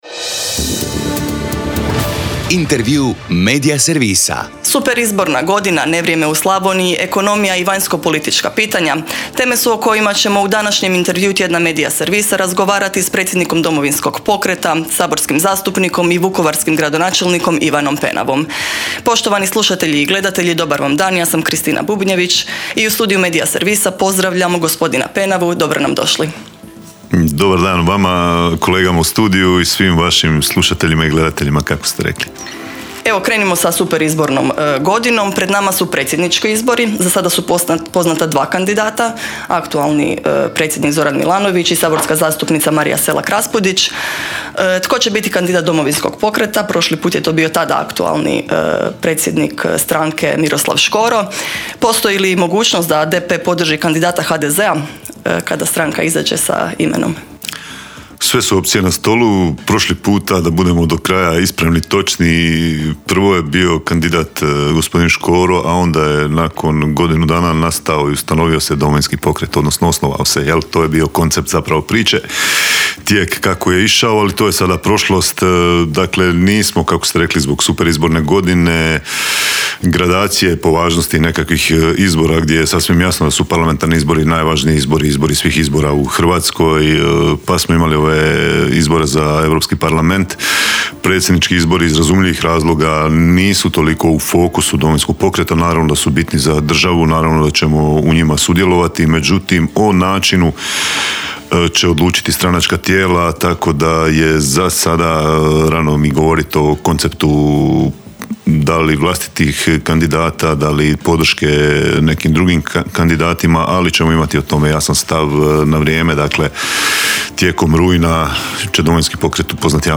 ZAGREB - Superizborna godina, nevrijeme u Slavoniji, ekonomija i vanjskopolitička pitanja - teme su o kojima smo u Intervjuu tjedna Media servisa razg...